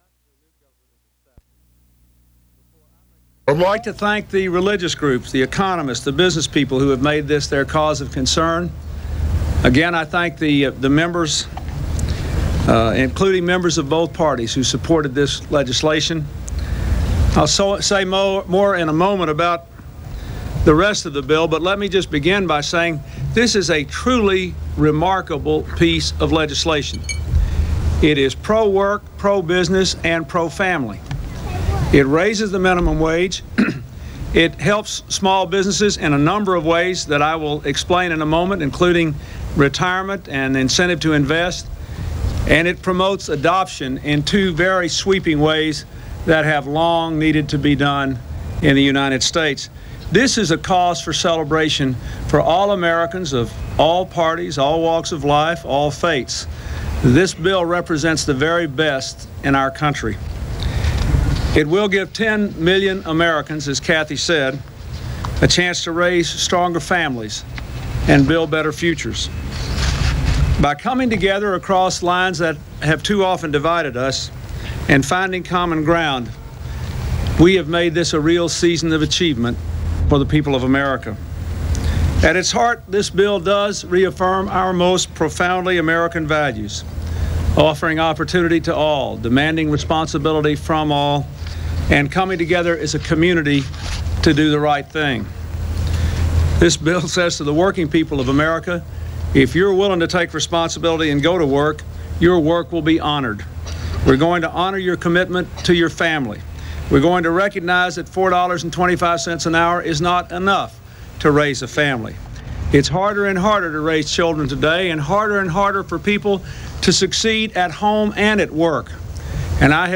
Signing ceremonies
Broadcast on CNN, Aug. 20, 1996.